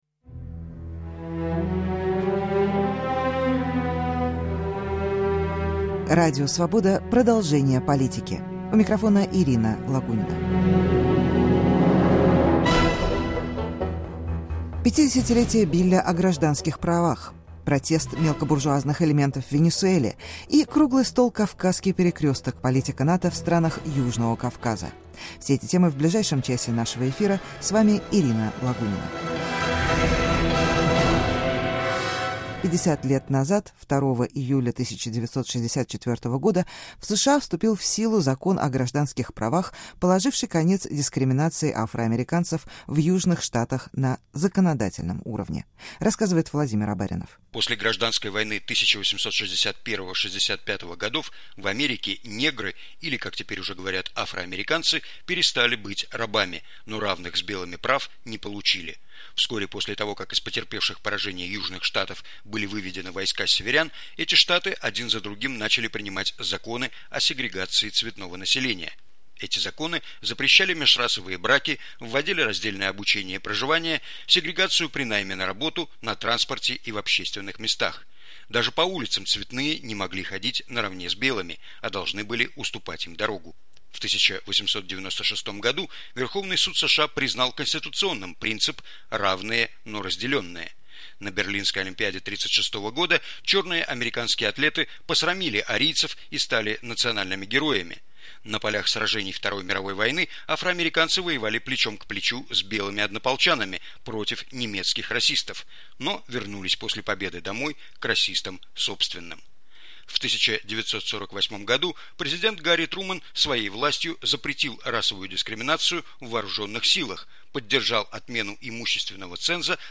50-летие Билля о гражданских правах. Протест «мелкобужуазных элементов» в Венесуэле. Круглый стол "Кавказский перекресток": политика НАТО в странах Южного Кавказа.